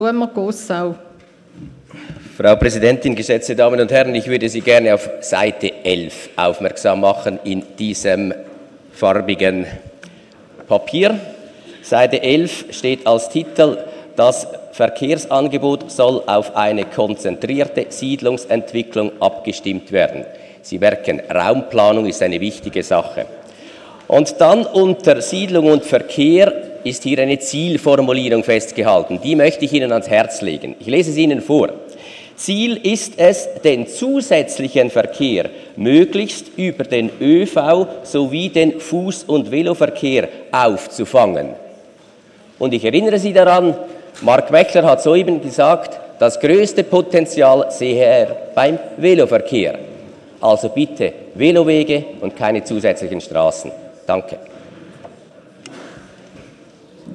18.9.2018Wortmeldung
Session des Kantonsrates vom 17. bis 19. September 2018